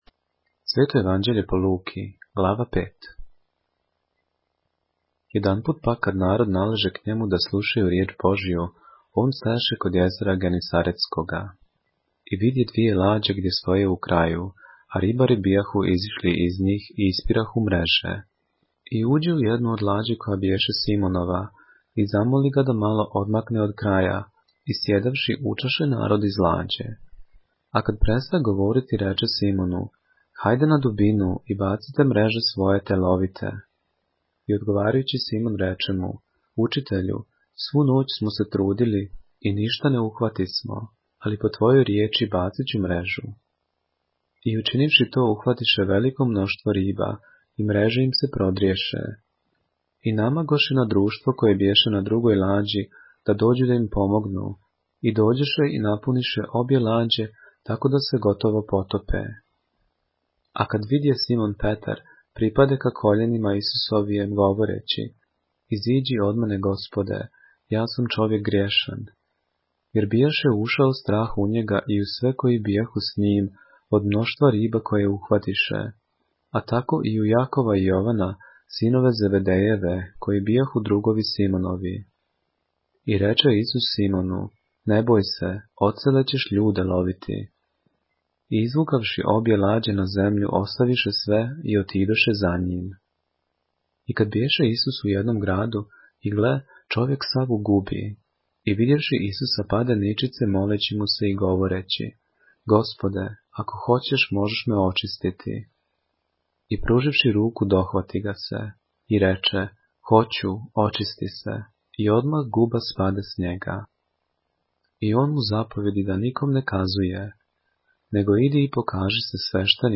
поглавље српске Библије - са аудио нарације - Luke, chapter 5 of the Holy Bible in the Serbian language